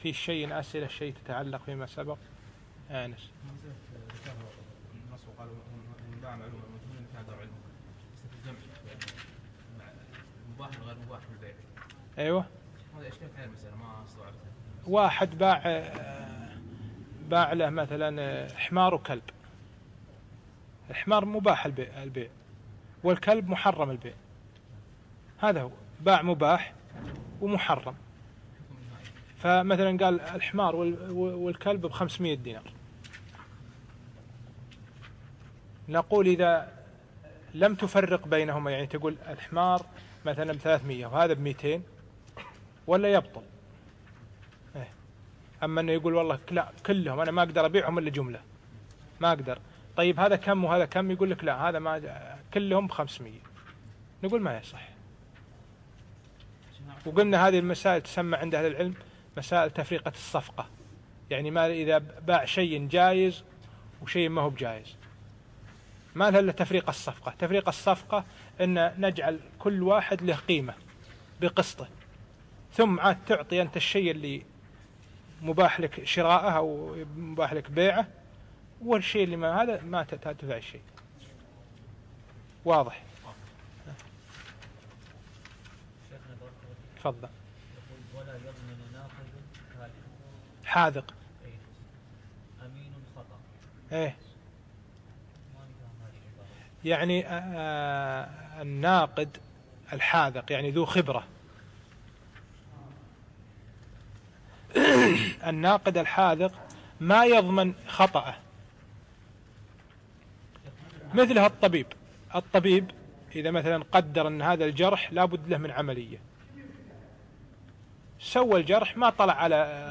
الدرس الثاني من باب الربا إلى نهاية باب السلم